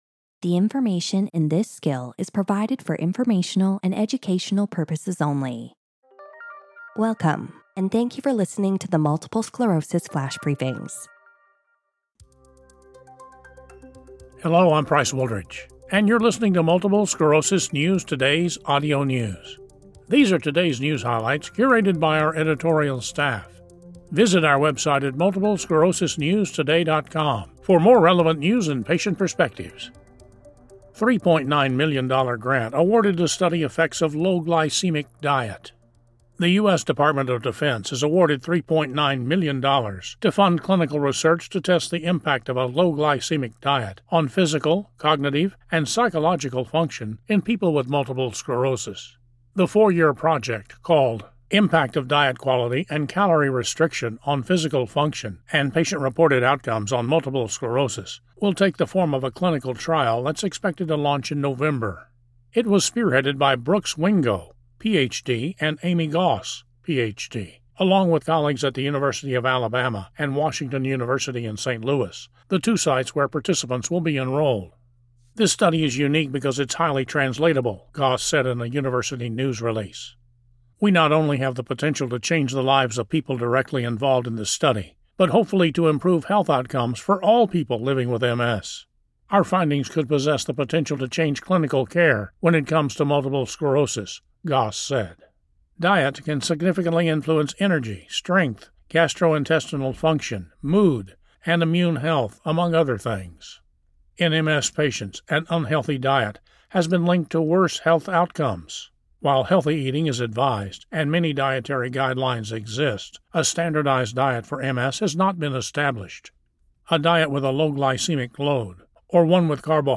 reads a news article on how the U.S. Department of Defense awarded $3.9 million to study the mental and physical health of multiple sclerosis patients following a low-glycemic diet.